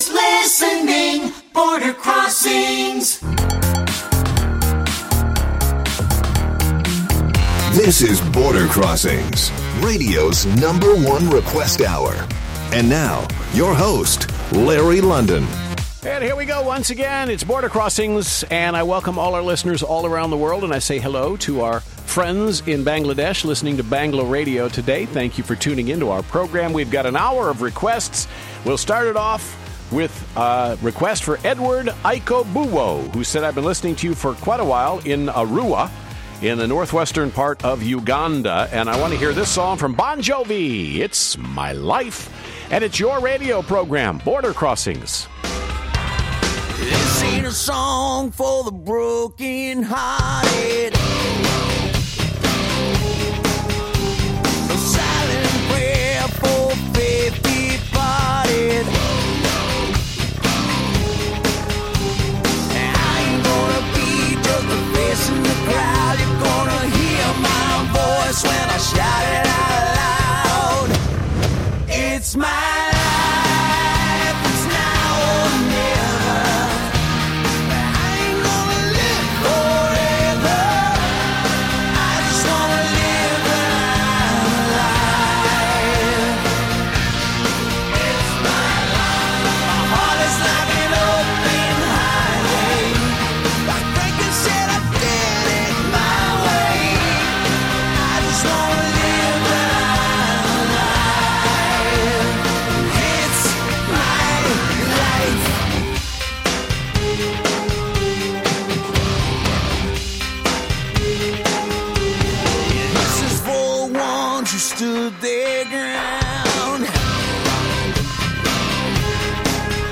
VOA’s live worldwide international music request show